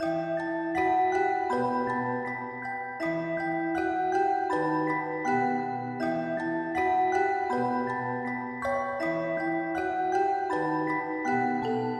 驾驭空灵的合成器钟声1
描述：B小调的洞穴式合成器钟声，背景是敲击式的刮擦声。
标签： 80 bpm Trap Loops Bells Loops 2.02 MB wav Key : B
声道立体声